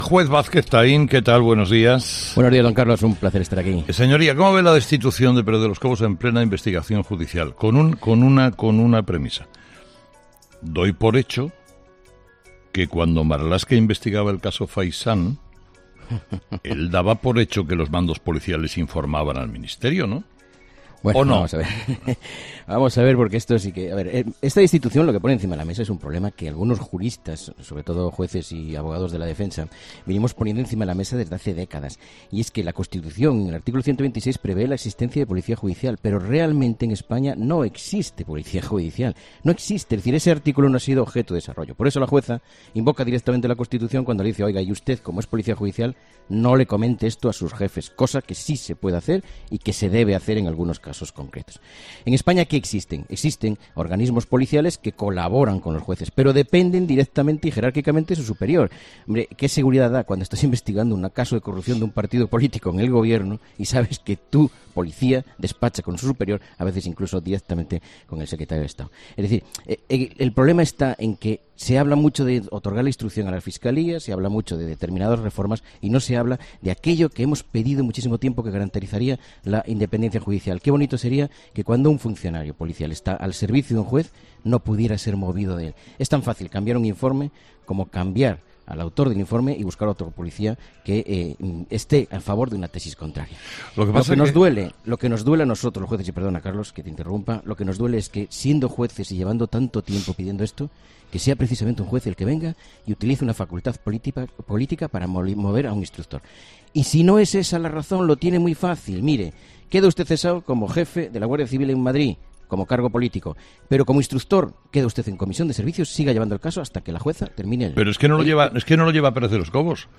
Entrevistado: "José Antonio Vázquez Taín"